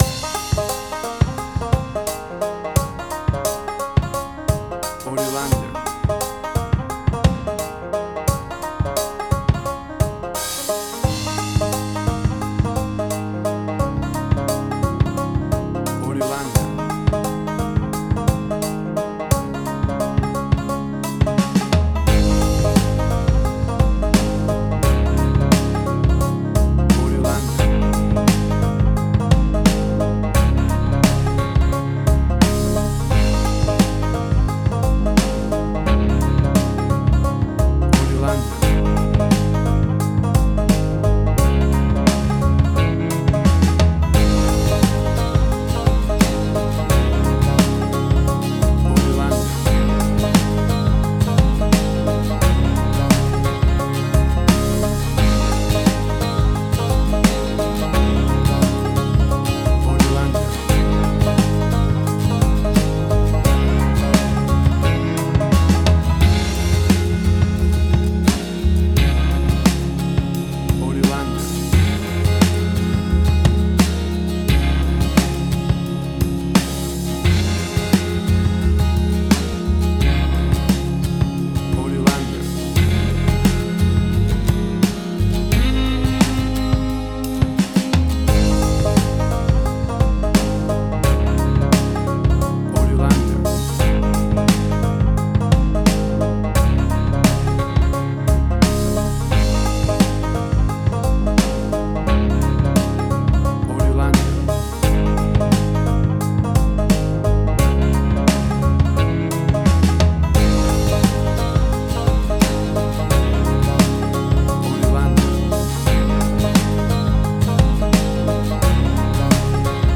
great for fun upbeat country music parties and line dances.
Tempo (BPM): 87